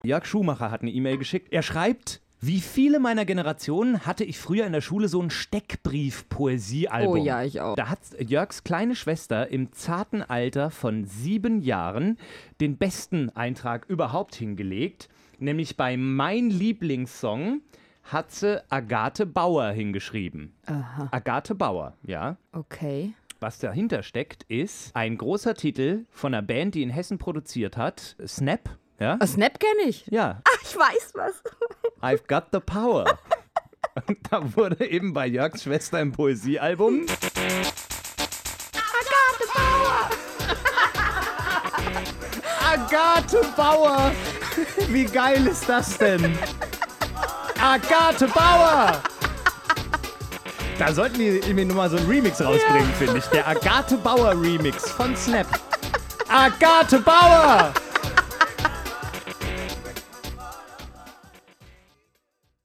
Radioversprecher und Movies
An den Anfang habe ich drei *.MP3 Stücke mit Radiopannen, Versprechern gestellt.